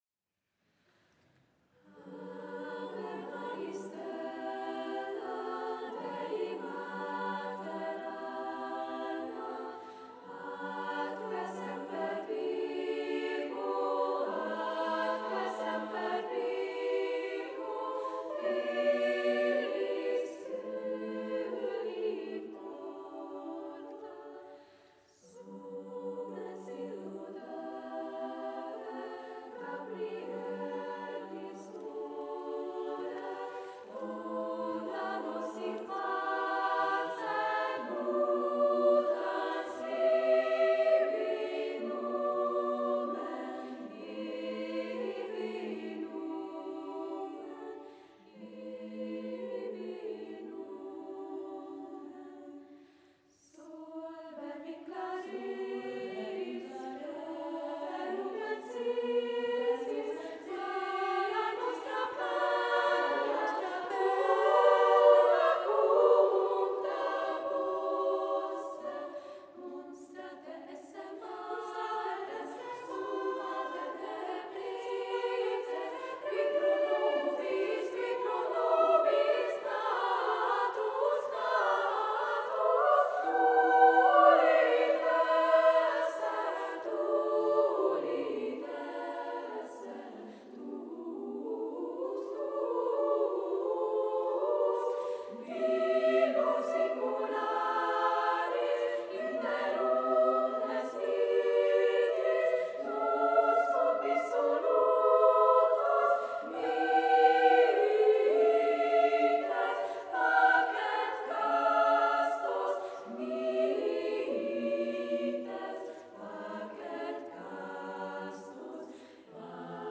EMMI – Az iskola leánykarának programja a 2018/2019-es tanévben